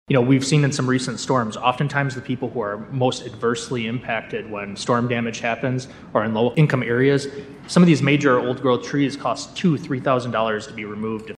Commissioner Chris Preadel says that’s 900 new trees.